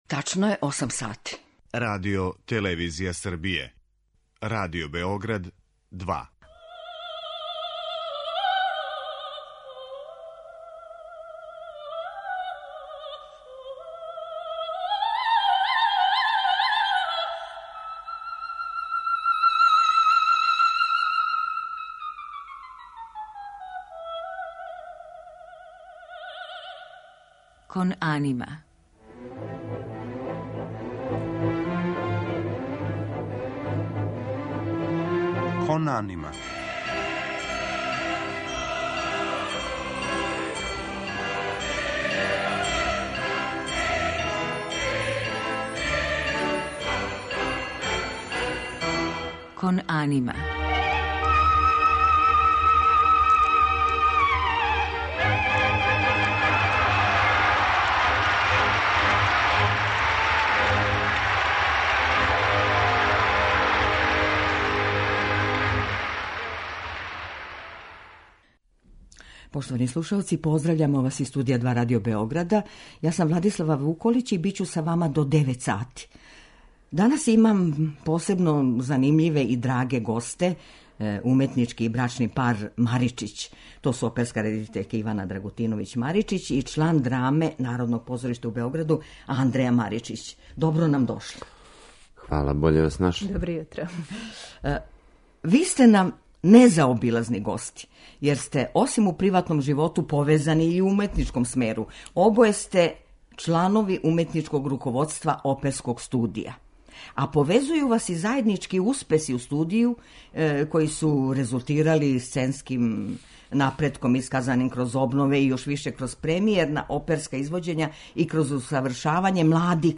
У музичком делу емитоваћемо фрагменте из Моцартове опере "Чаробна фрула" и опере "Сестра Анђелика", Ђакома Пучинија - две сценске продукције овог студија које је музичка јавност означила као успешну презентацију младих оперских извођача.